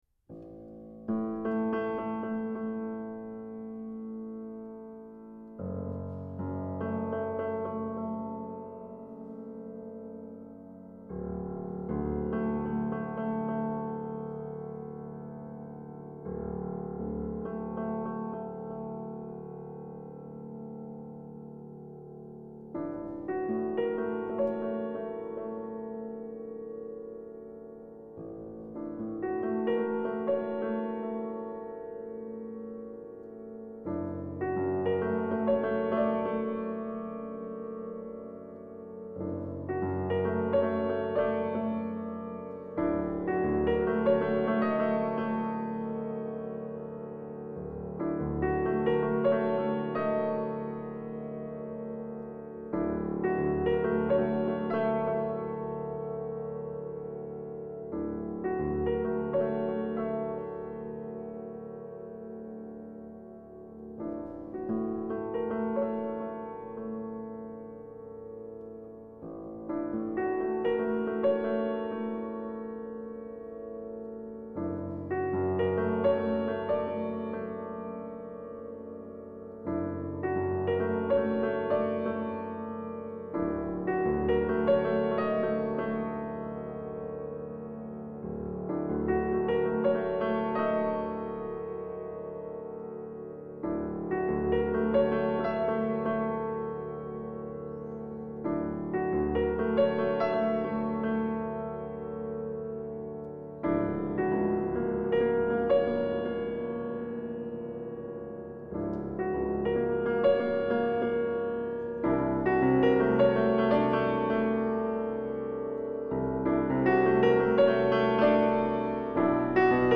Recorded at Ballhaus Naunynstrasse